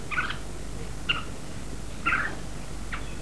Here are a few samples of birds songs I recorded in the hotel's gardens.
Bulbuls
Bulbul2  (76 Ko)
bulbul2.wav